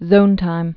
(zōntīm)